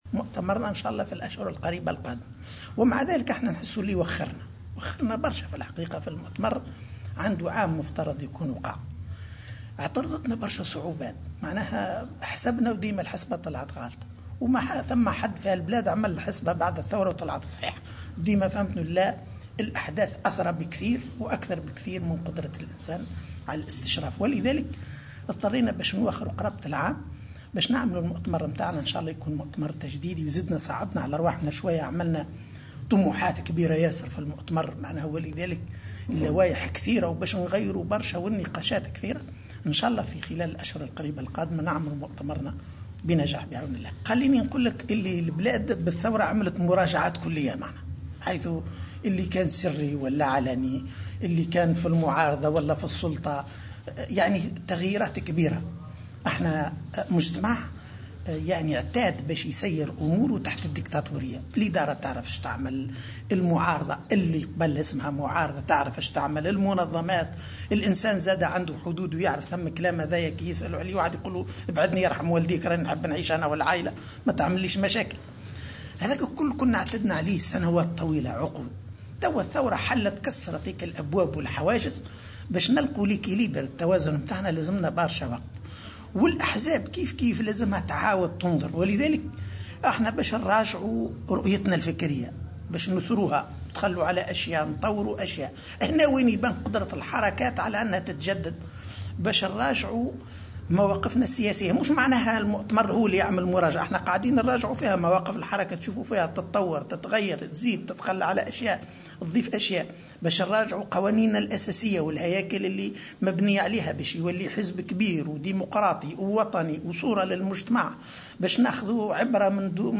قال أمين عام حركة النهضة علي العريض في تصريح للجوهرة أف أم خلال إشرافه اليوم الأحد 20 ديسمبر 2015 على إجتماع قاعدي في منطقة السواسي من ولاية المهدية استعدادا لمؤتمر الحركة إن النهضة ستراجع رؤيتها الفكرية ومواقفها السياسية وقوانينها الأساسية والهياكل المبنية عليها.